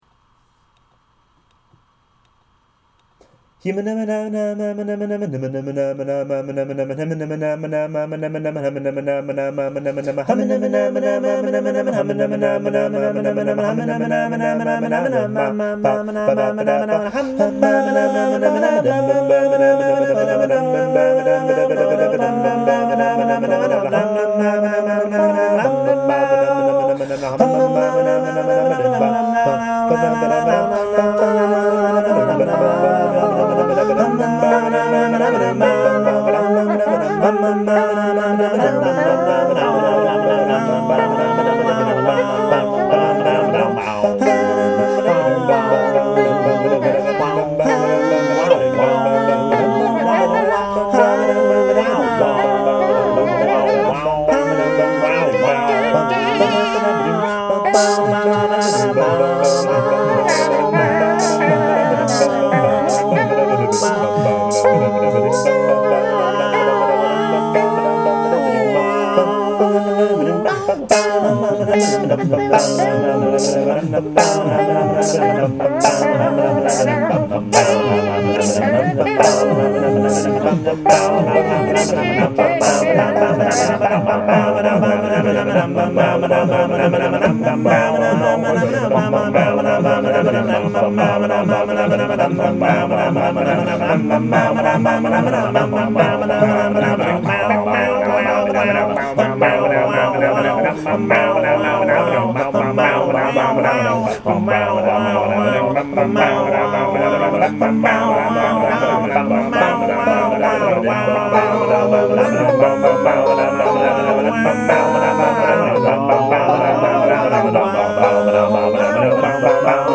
Since our Internet was down due to a dysfunctional DNS server, I wound up breaking out GarageBand and started fiddling around.
What I discovered is that I am my own a capella group.
Not very creative, but since there are no lyrics, well, it was as good a title as anything.